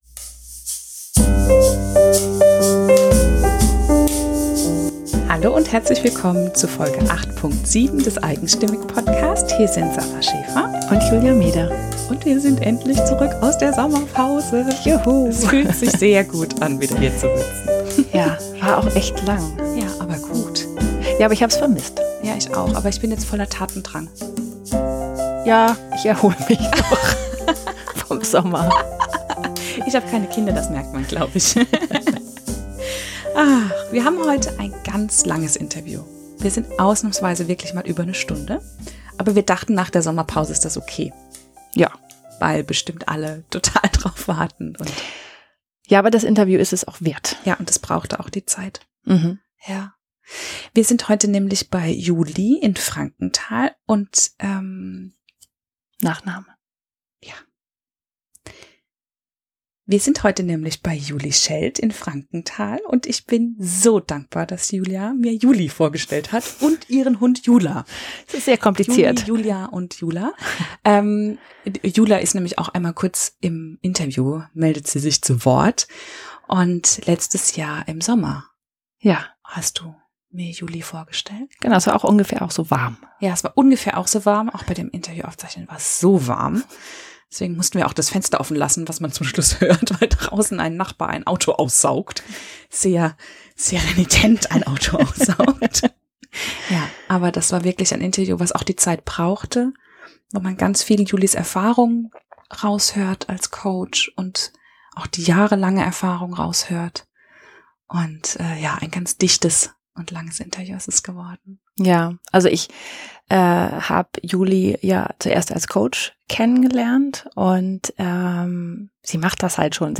Im Hochsommer bei offenem Fenster, Wassermelone und Limonade